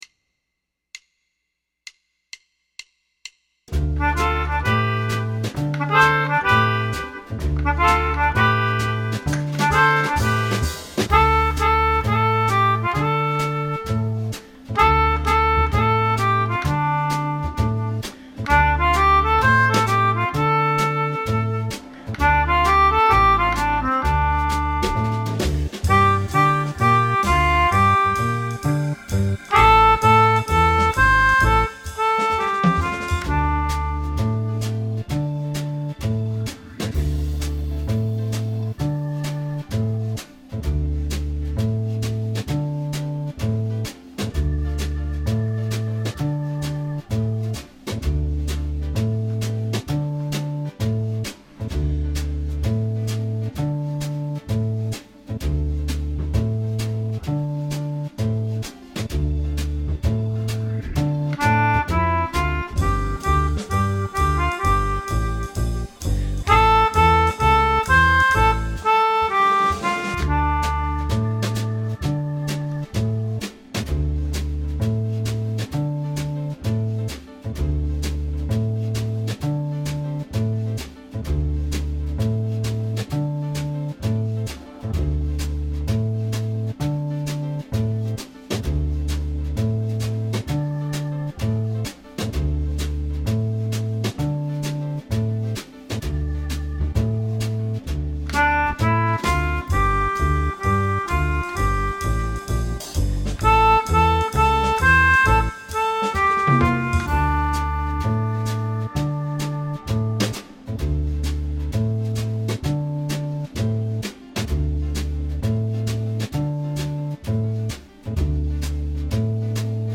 2.6 | Eine Play-Along-Aufnahme für dich
Die zweitbeste Lösung: spiele zu einer Play-Along-Aufnahme, auf der die ganze Begleitband zu hören ist.
Play-Along-GROOVE-mit-Bridge.mp3